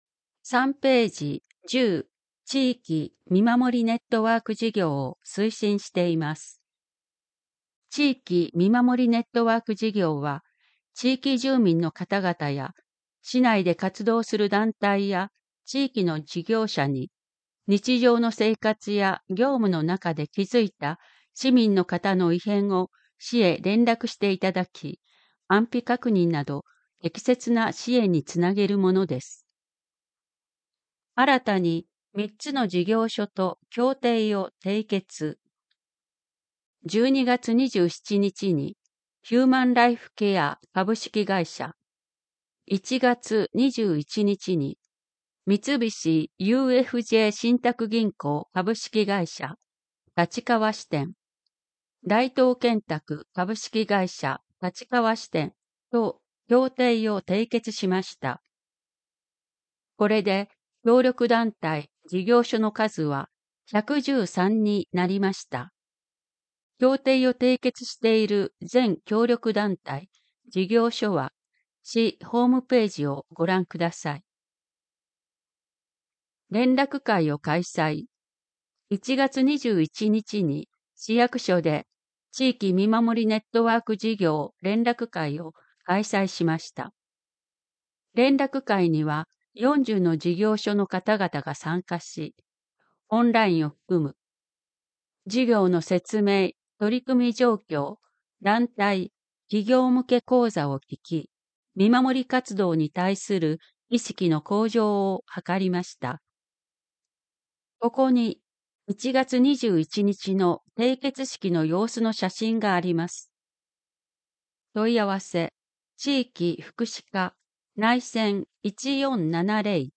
MP3版（声の広報）